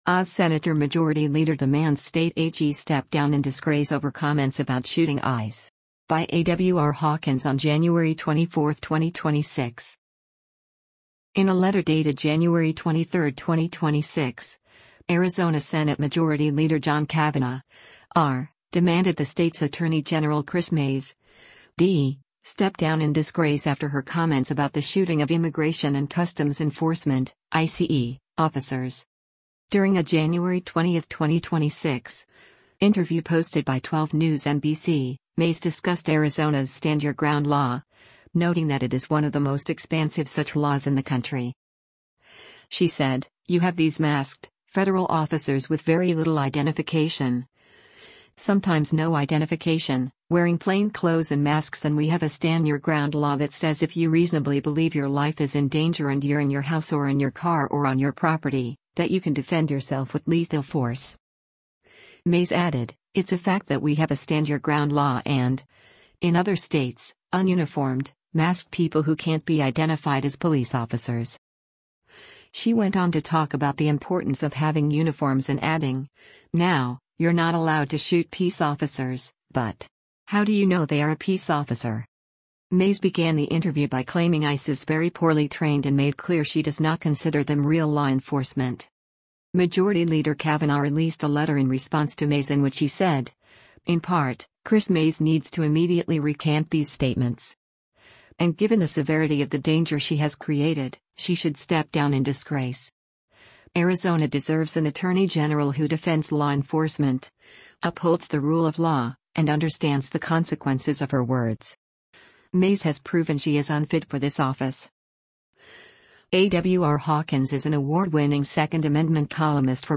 During a January 20, 2026, interview posted by 12 News NBC, Mayes discussed Arizona’s “Stand Your Ground” law, noting that it is one of the most expansive such laws in the country.